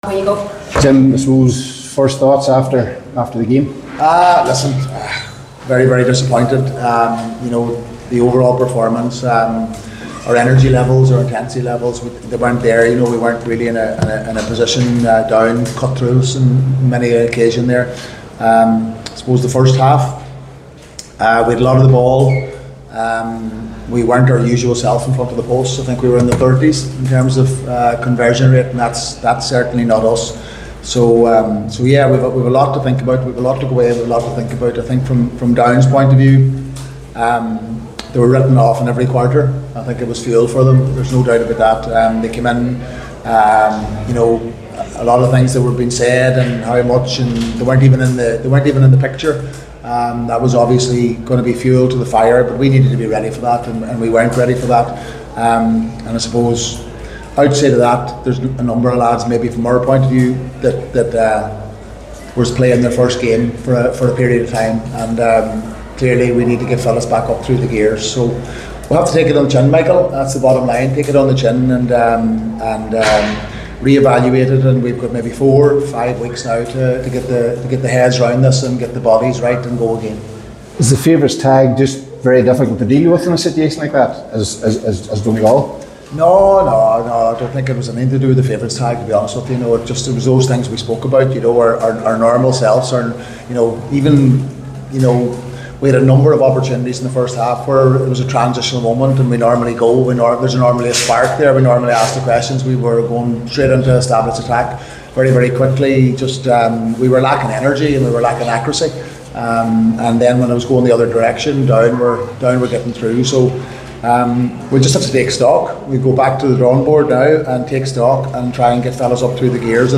A disappointed Donegal Manager Jim McGuinness said afterwards they weren’t at the levels that were required and they weren’t ready for what Down brought to the game: